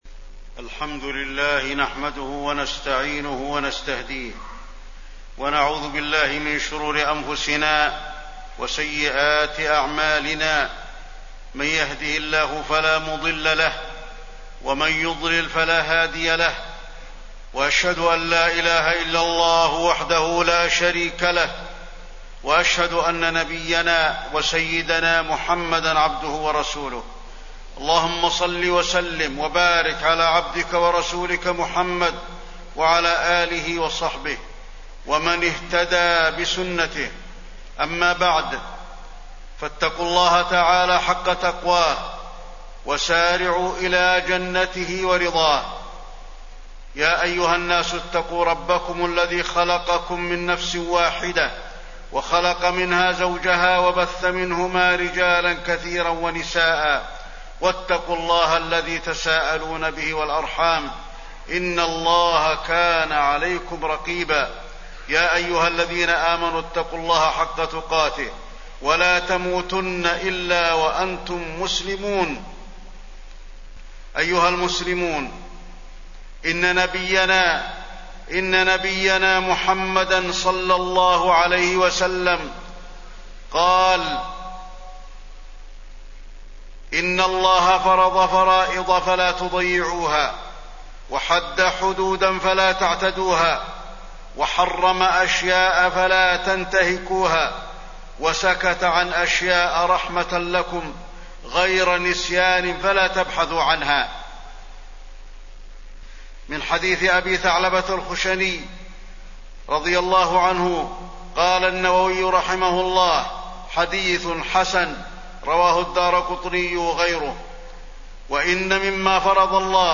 تاريخ النشر ٢٨ صفر ١٤٣١ هـ المكان: المسجد النبوي الشيخ: فضيلة الشيخ د. علي بن عبدالرحمن الحذيفي فضيلة الشيخ د. علي بن عبدالرحمن الحذيفي الأمر بالمعروف والنهي عن المنكر